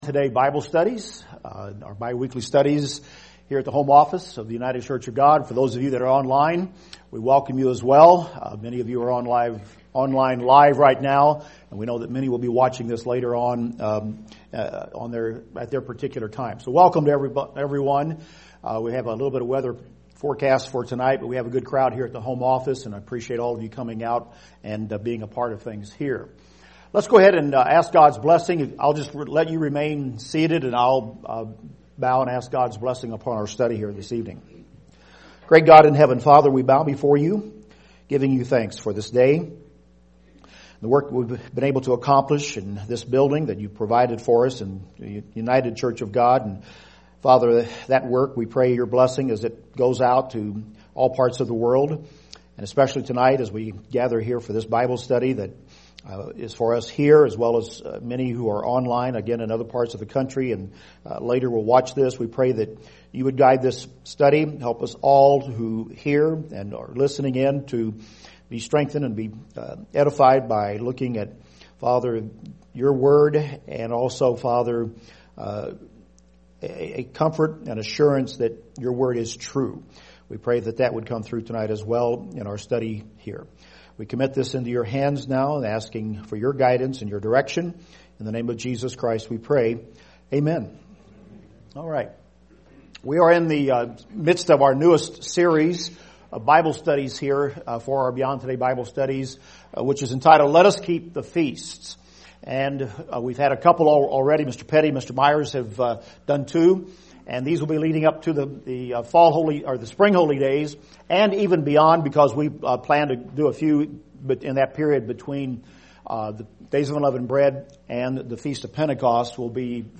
This is part 2 in the Bible study series: Let Us Keep the Feasts. Did the Exodus happen and why is it important?